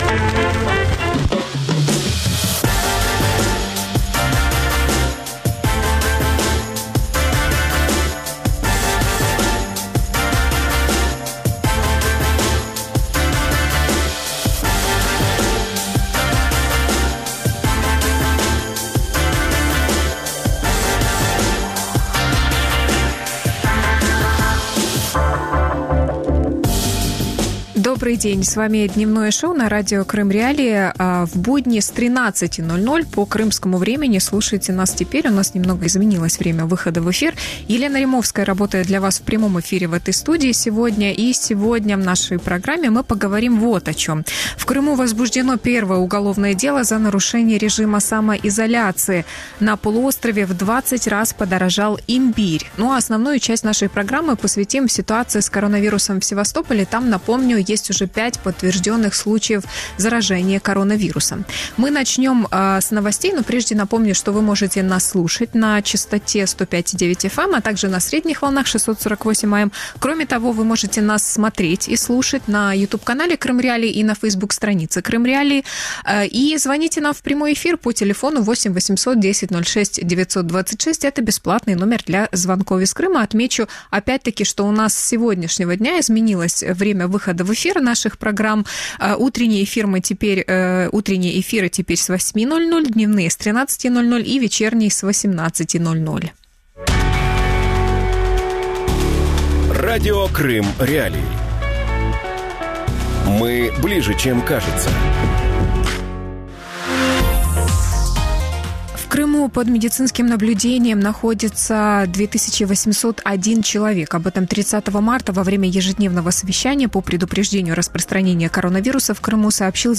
Коронавирус в Севастополе | Дневное ток-шоу